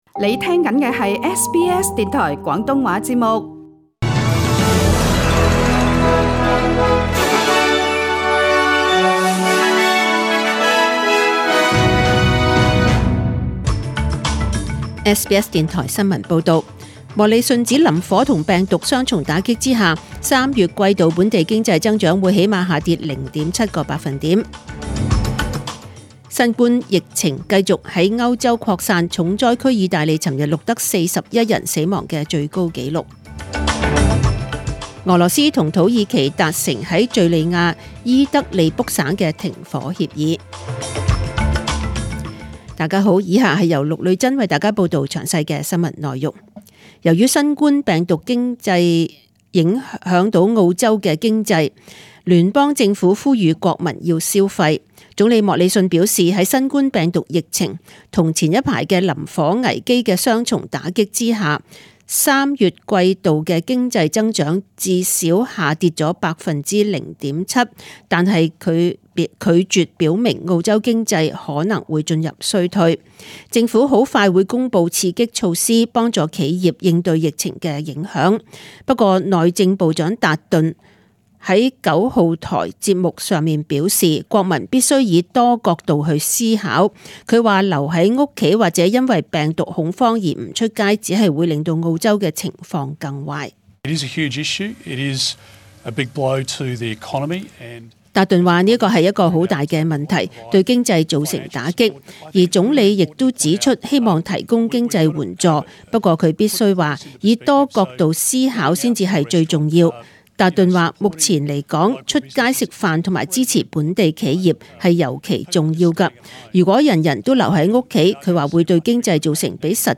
请收听本台为大家准备的详尽早晨新闻